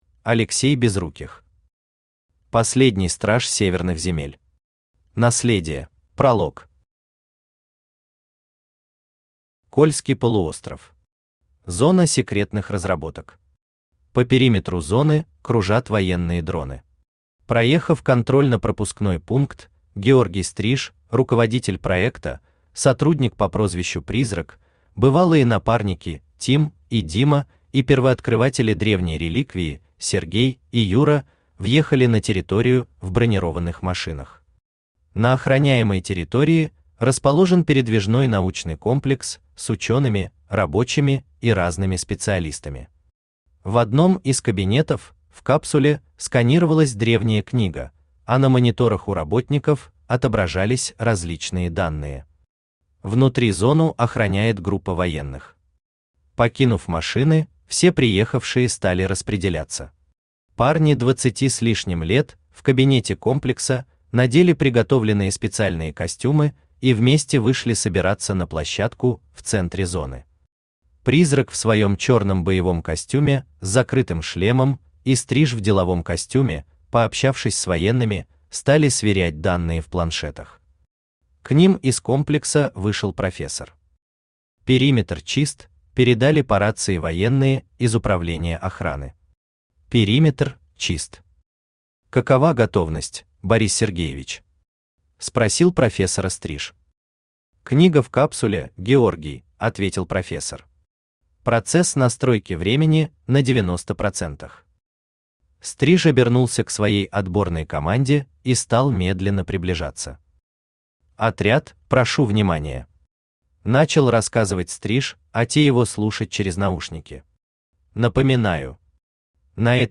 Аудиокнига Последний страж северных земель. Наследие | Библиотека аудиокниг
Наследие Автор Алексей Олегович Безруких Читает аудиокнигу Авточтец ЛитРес.